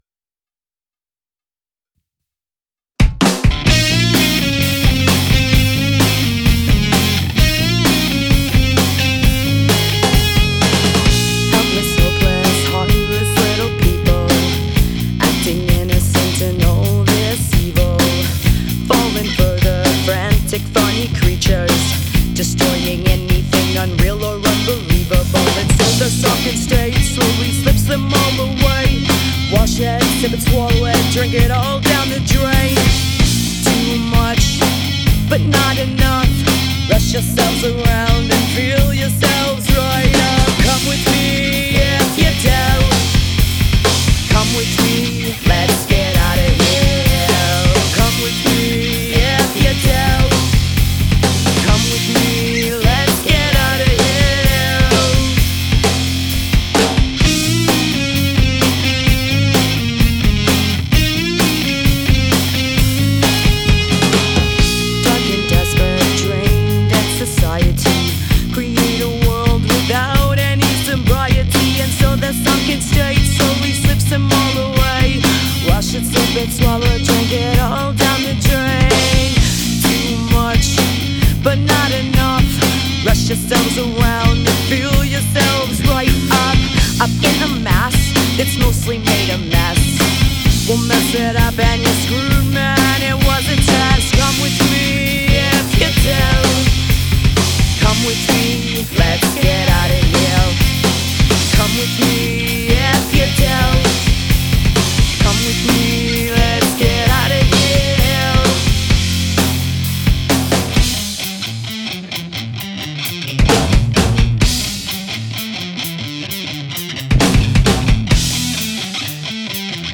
female rocker
Rock
Female fronted Rock Music
Mainstream Rock with catchy hooks and unique vocals